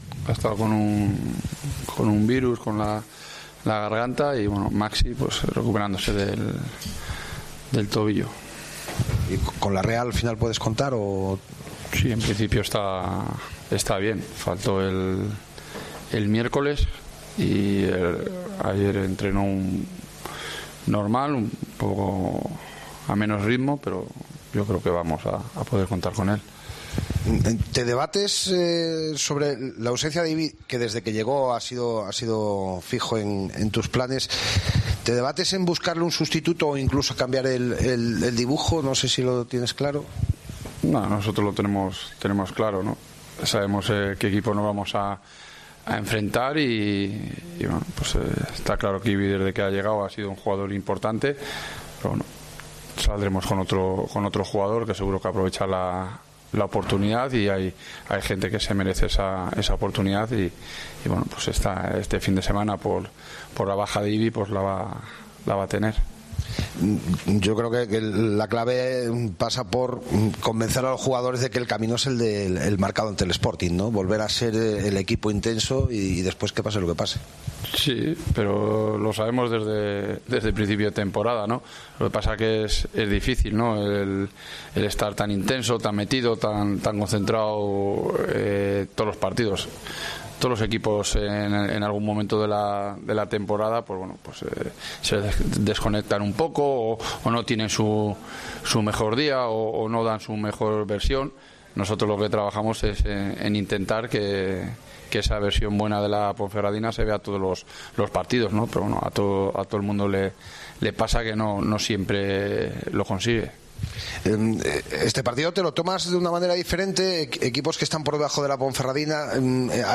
AUDIO: Escucha aquí las palabras del míster de la Deportiva Ponferradina, Jon Pérez Bolo